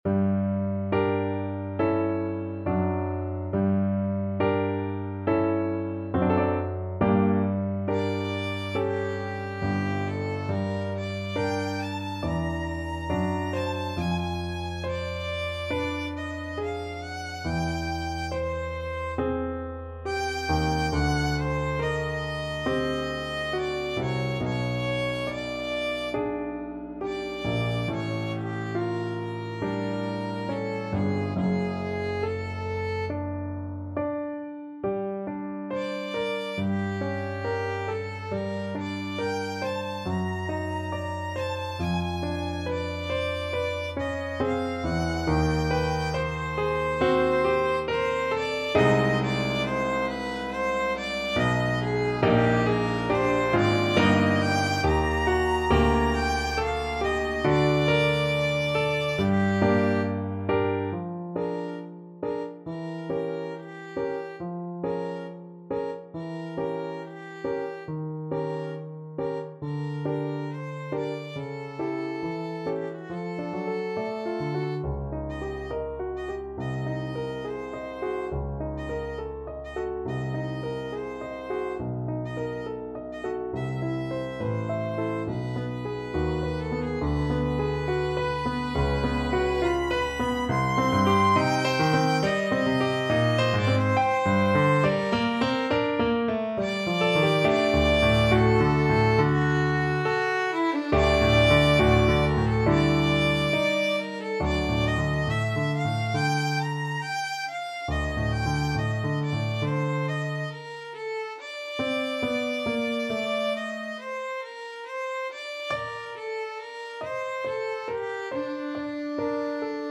Violin
Bb major (Sounding Pitch) (View more Bb major Music for Violin )
4/4 (View more 4/4 Music)
~ = 69 Andante con duolo
Classical (View more Classical Violin Music)